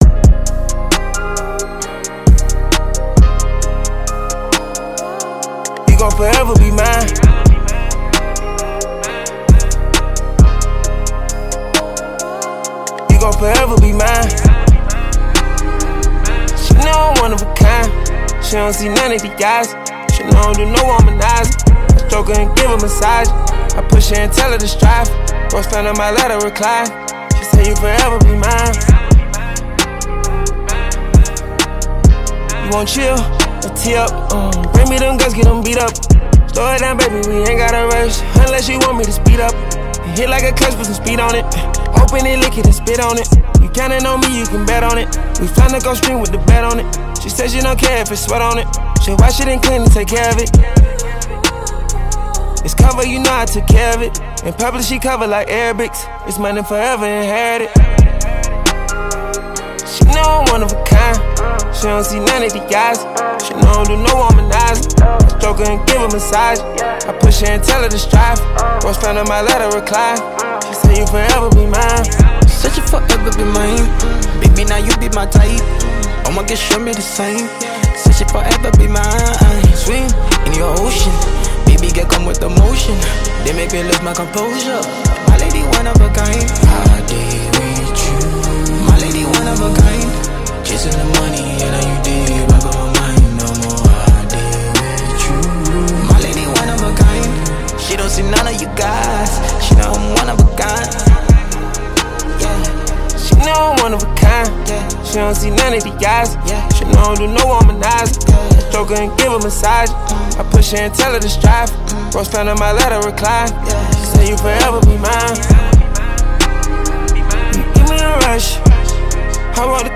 ” an outstanding and mood-evoking lyrical volume